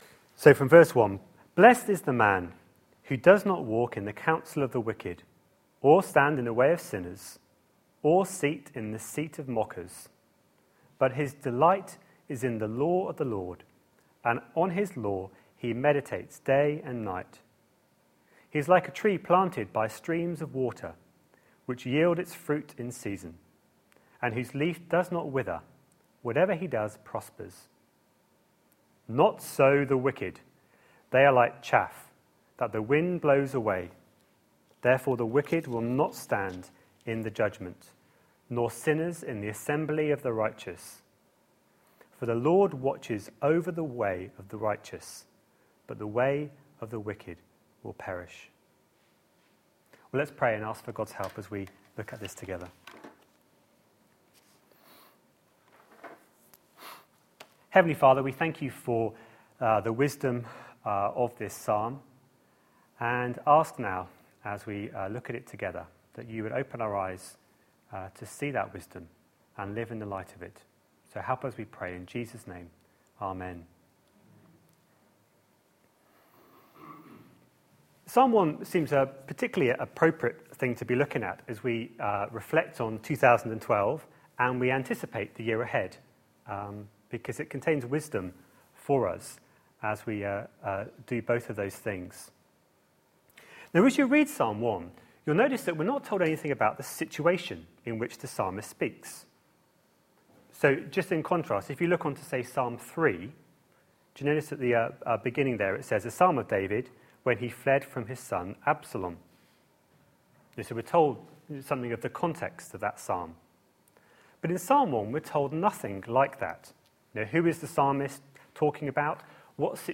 A sermon preached on 30th December, 2012.